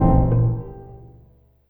59 FX 1   -L.wav